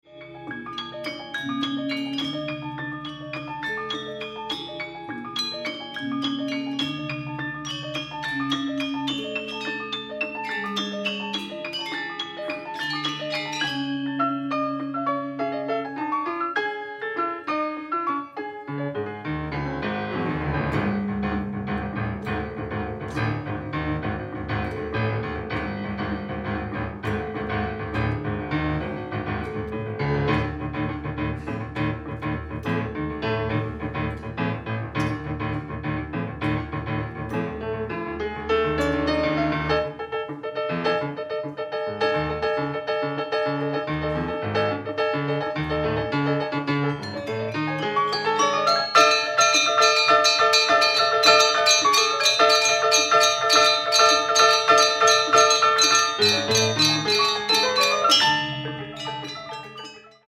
kbd, Balinese gamelan